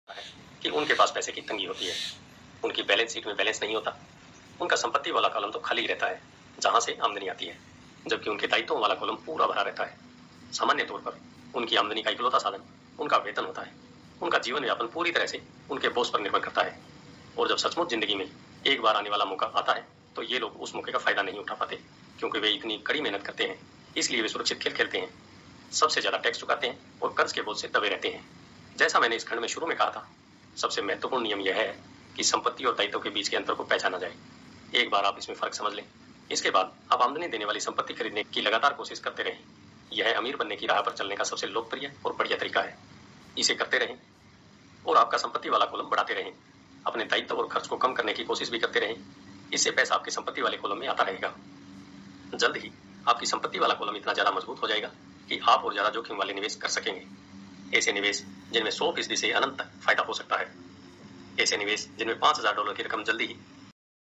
Rich Dad Poor Dad: The Secret to Be Rich – Audiobook
रिच डैड पुअर डैड: अमीर बनने का रहस्य – ऑडियोबुक